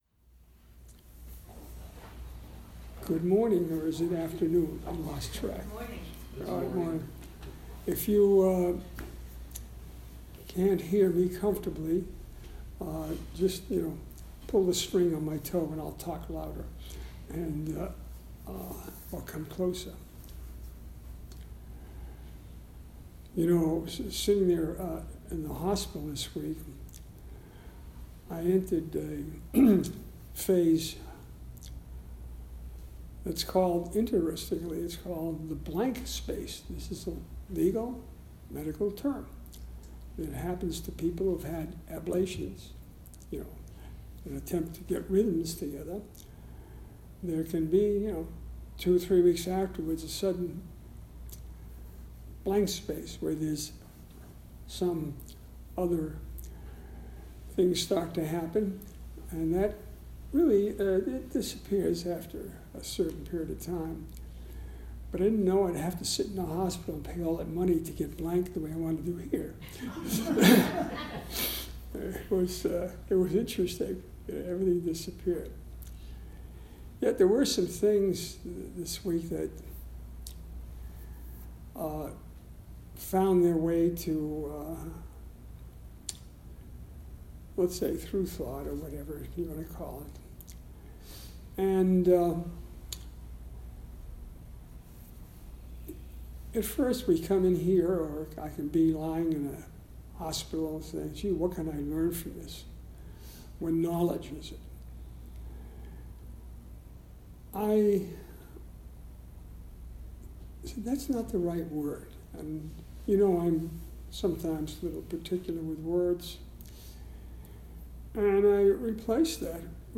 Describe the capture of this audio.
August 11th 2018 Southern Palm Zen Group